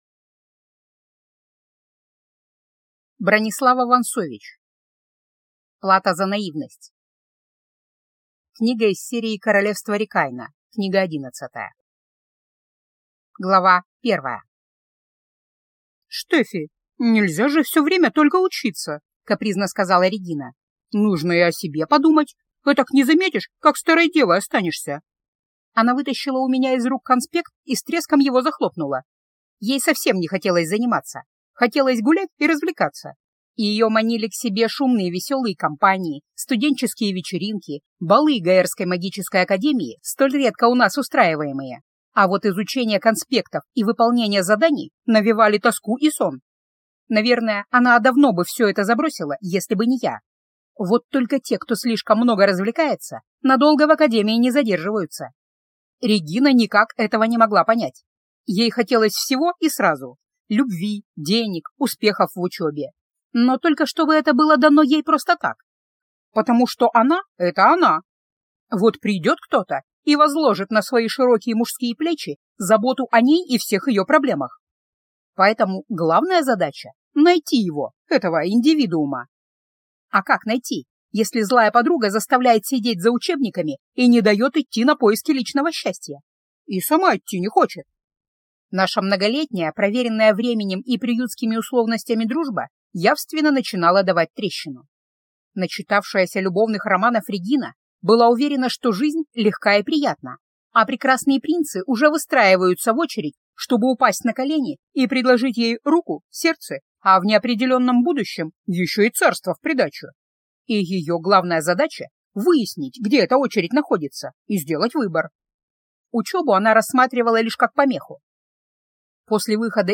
Аудиокнига Плата за наивность - купить, скачать и слушать онлайн | КнигоПоиск